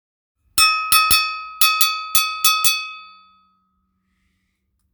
ドゥンドゥン鉄ベル 小
小さいサイズの手作りの鉄製ベル。本格的な演奏に、ジュンジュンに装着して使用します。
素材： 鉄